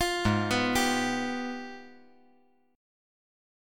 AbmM13 Chord
Listen to AbmM13 strummed